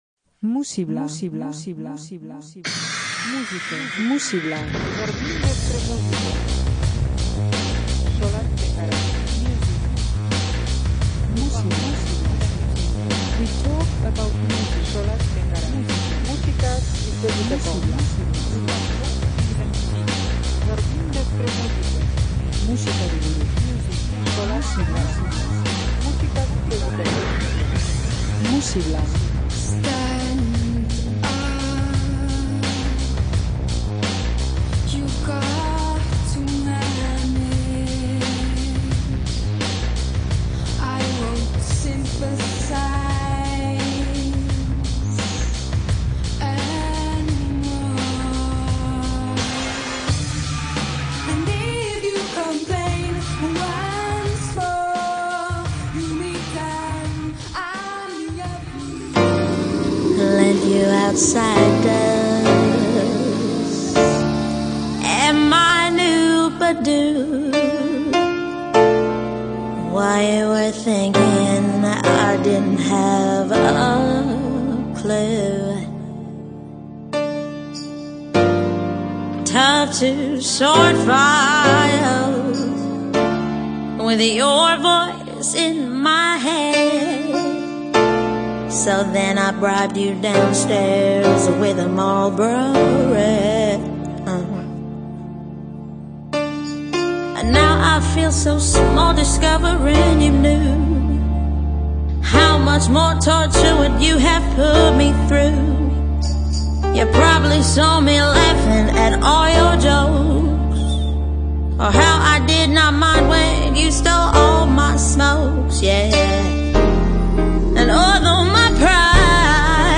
Zein baino zein pertsonalago, zein baino zein beroago.